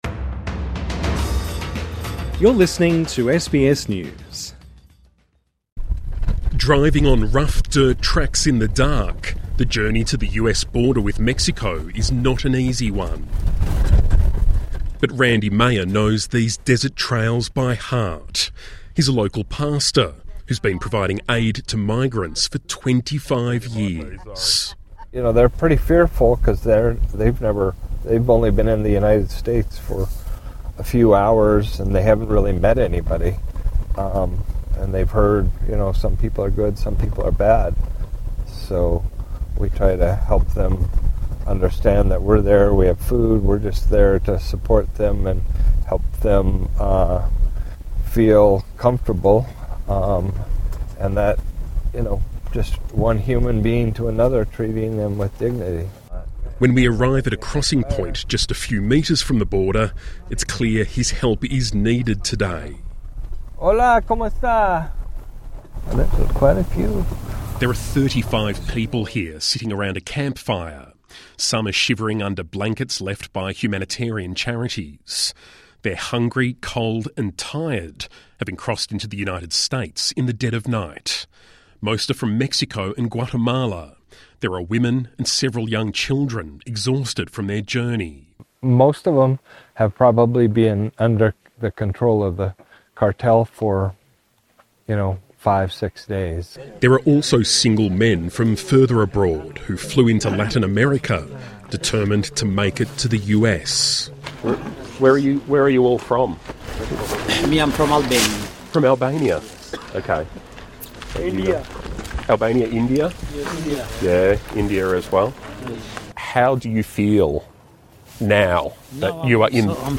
SBS News has travelled to the very south of Arizona to see if Mr Trump’s rhetoric in any way matches the reality.